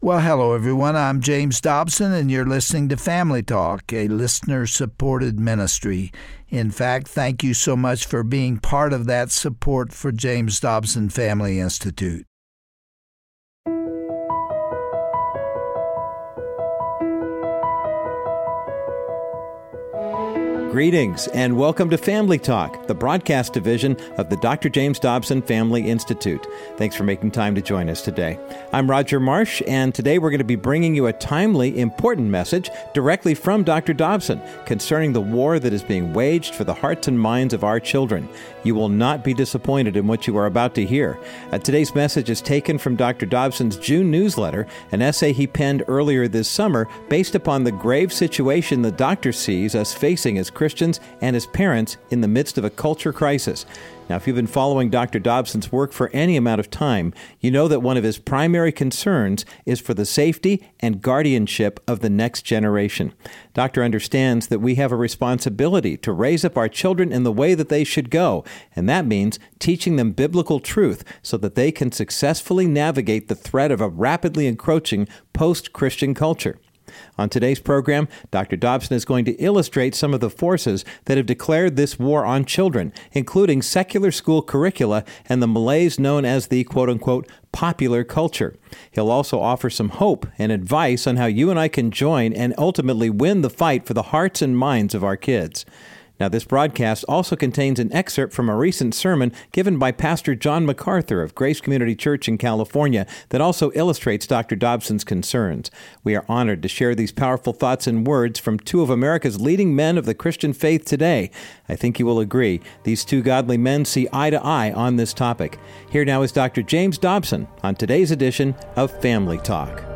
Dr. James Dobson shares from his June newsletter, reflecting on the increasing war against children and the battle for their minds and spirits. He expresses his grave concern for our culture and raises the alarm so that a twisted society does not dictate the lives of future generations. Also featured are excerpts of a sermon from Pastor John MacArthur, illustrating not only culture's slide into godlessness, but also its ultimate accountability to God.